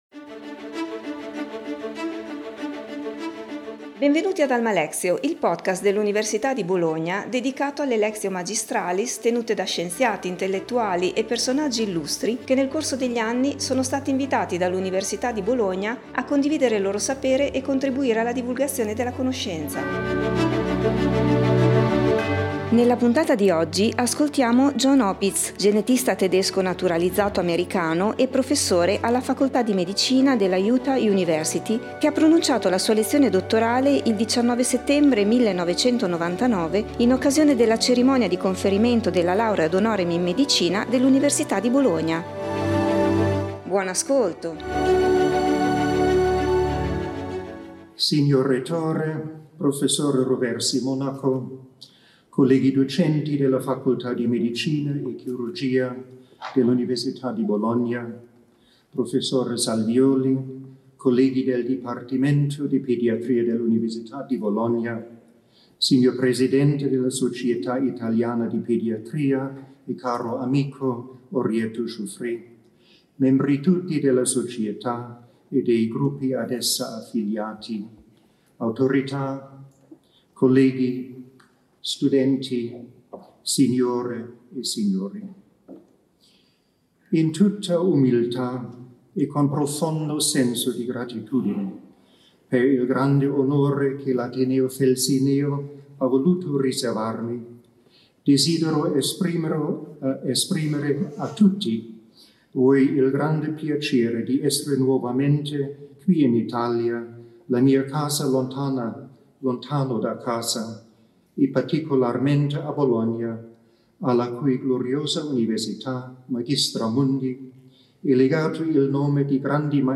John Opitz, genetista tedesco naturalizzato americano e professore alla Facoltà di Medicina della Utah University, ha pronunciato la sua lezione dottorale il 19 settembre 1999 in occasione della cerimonia di conferimento della Laurea ad honorem in Medicina dell'Università di Bologna.